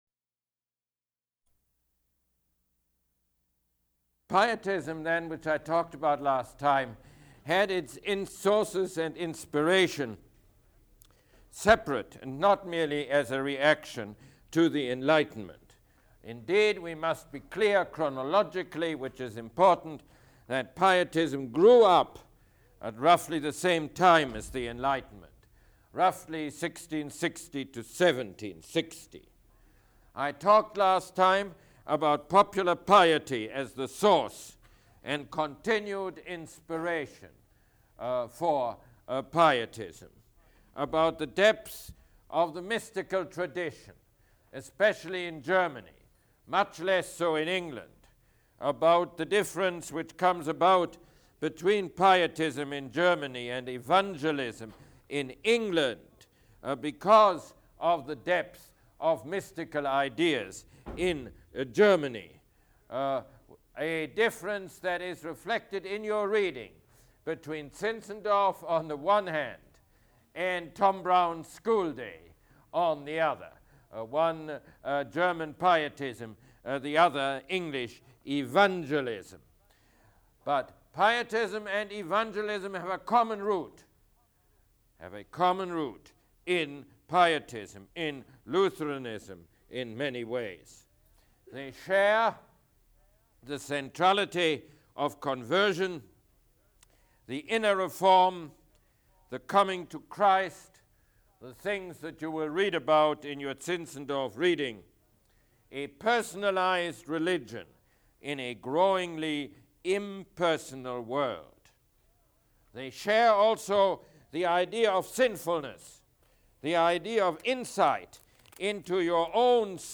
Mosse Lecture #10